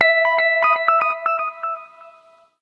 08_Echo.ogg